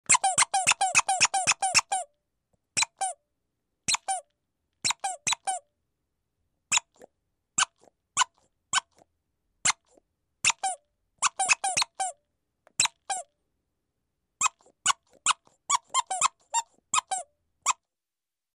Звук пищащей резиновой утки